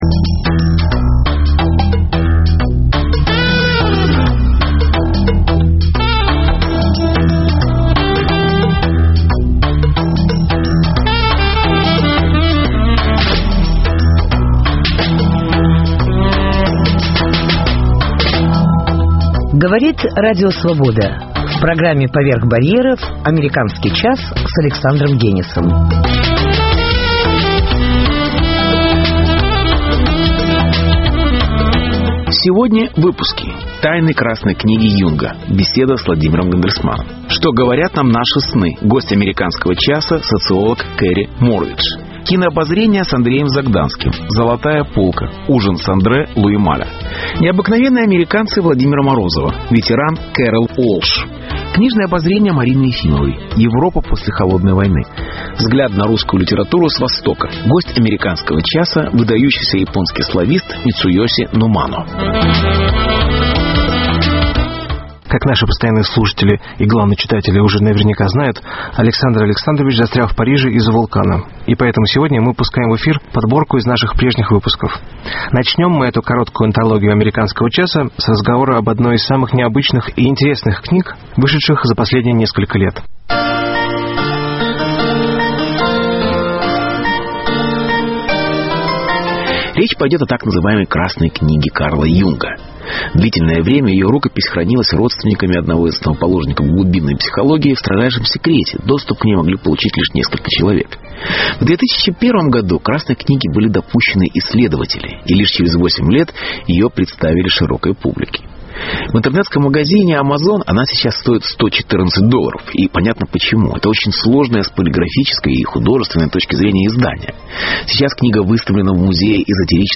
Беседа: Тайны «Красной книги» Юнга. Интервью: Что говорят нам наши сны.